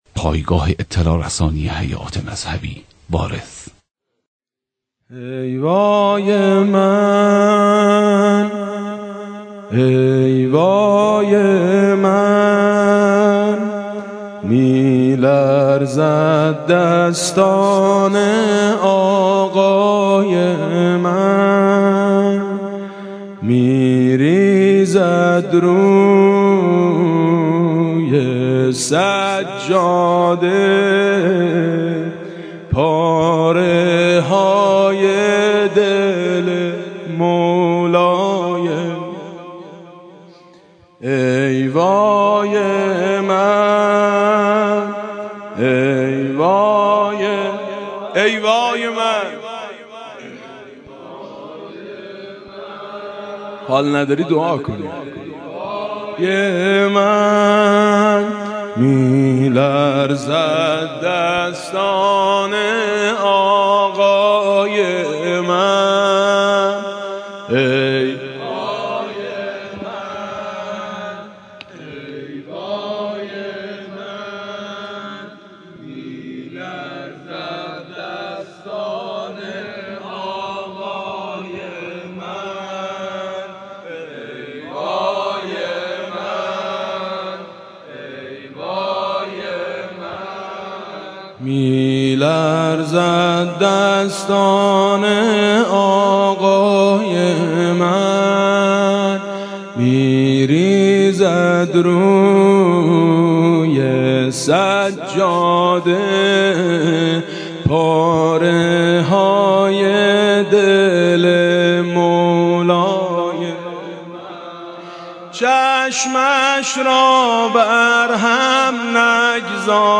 مداحی حاج میثم مطیعی به مناسبت شهادت امام صادق(ع)